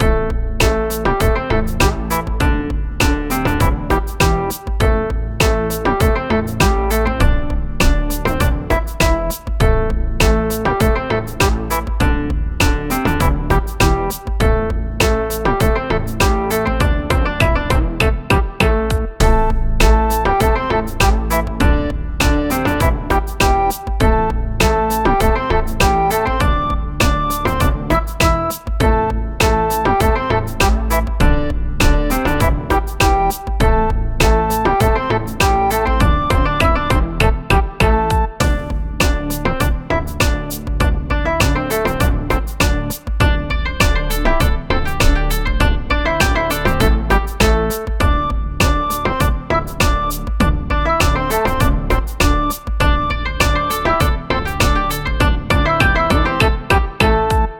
【イメージ】和風・おまつり など